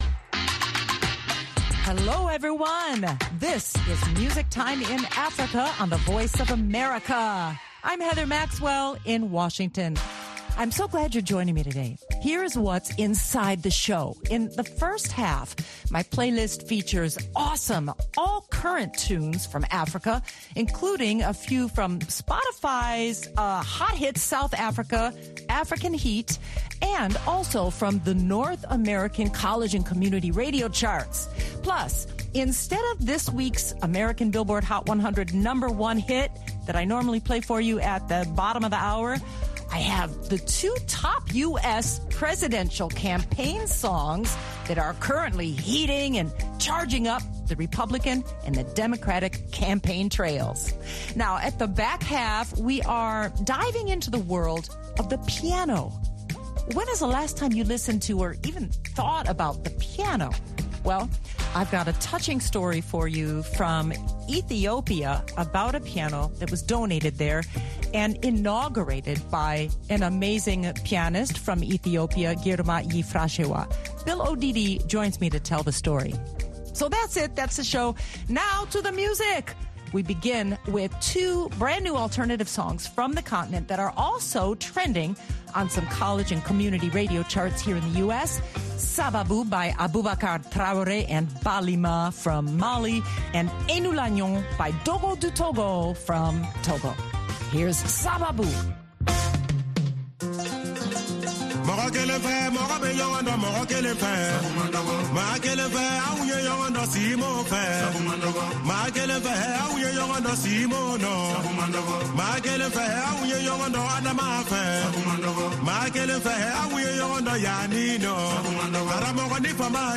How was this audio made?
Also included in the mix are US Presidential Election Candidates' #1 campaign theme songs. The feature story is on the arrival of a piano in a rural Ethiopian town for the all-girls school Pharo School.